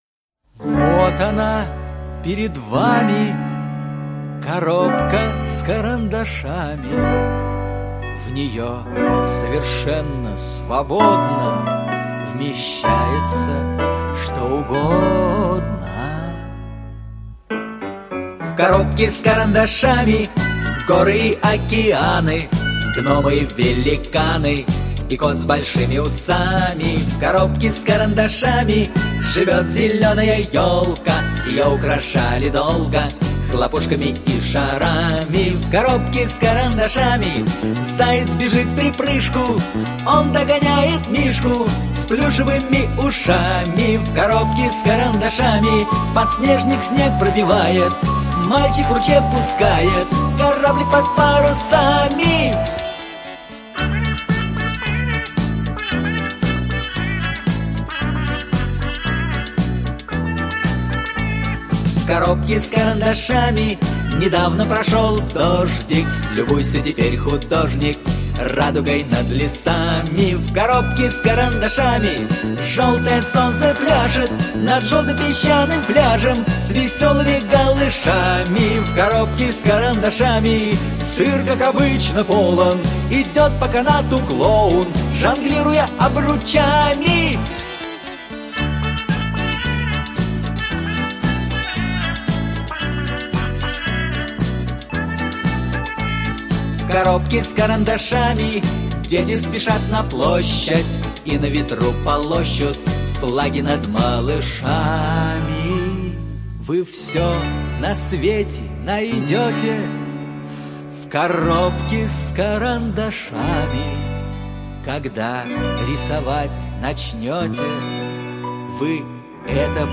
это песенка для малышей со смыслом.
задорную философскую песенку